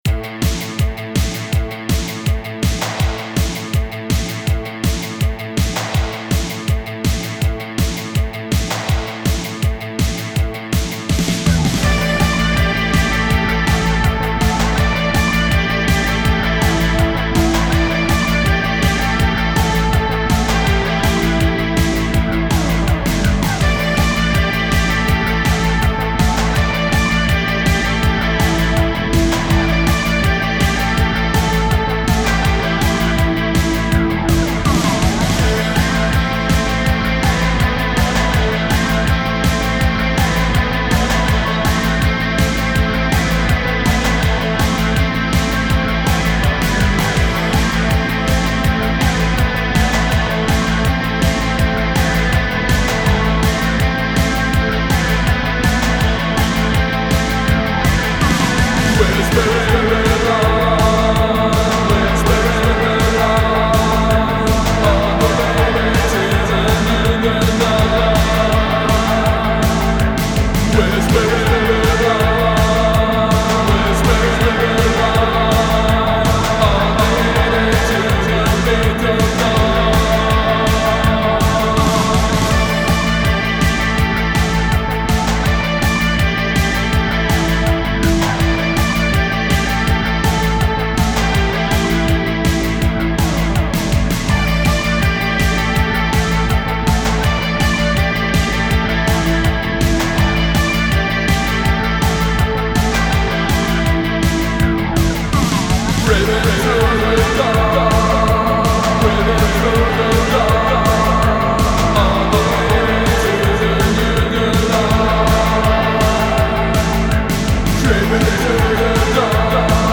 genre: Dark / Wave